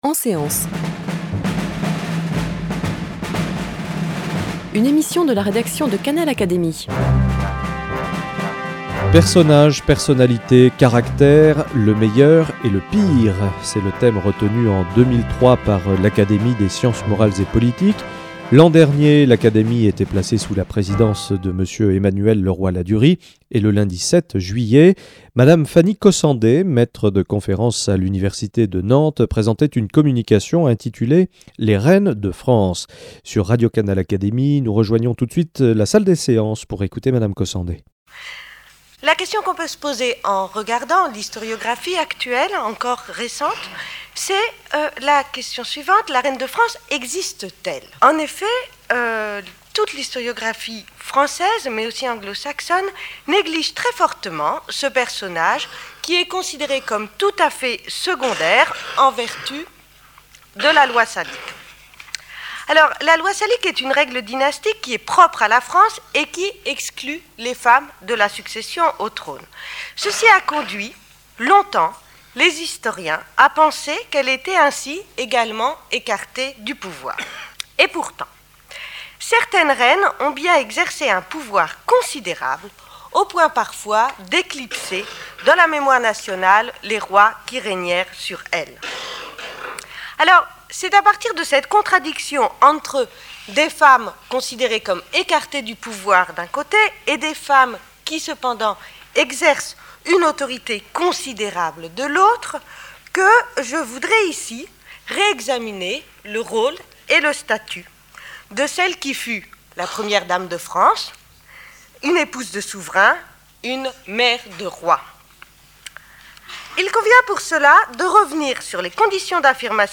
EN SÉANCE / Académie des sciences morales et politiques